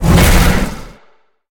File:Sfx creature hiddencroc chase os 04.ogg - Subnautica Wiki
Sfx_creature_hiddencroc_chase_os_04.ogg